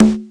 cch_10_percussion_one_shot_conga_low_nam.wav